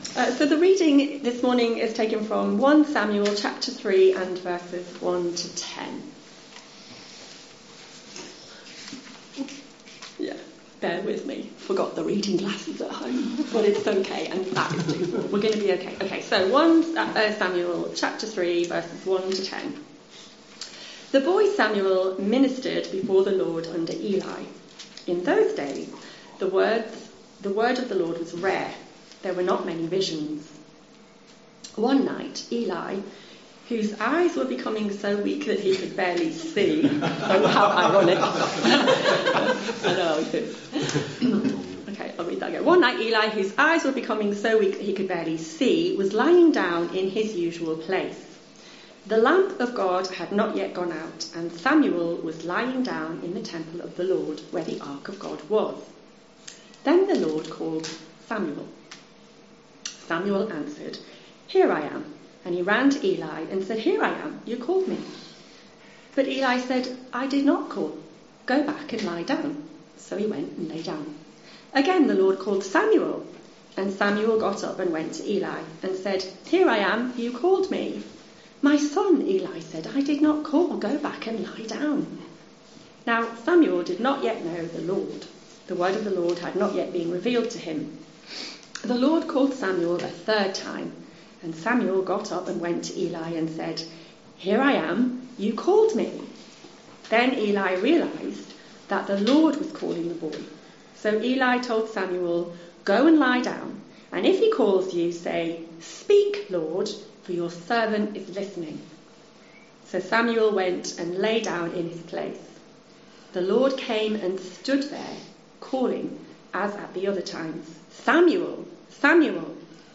In our first all-age service for 2023